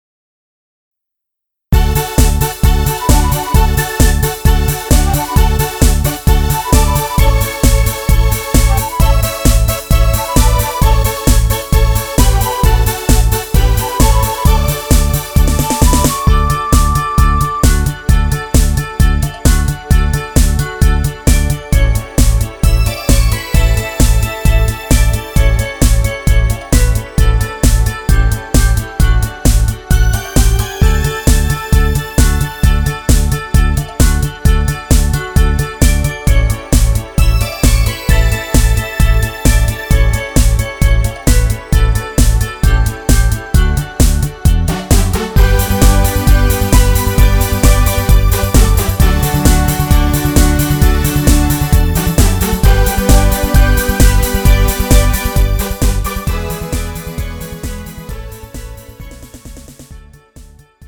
음정 D 키
장르 가요 구분 Pro MR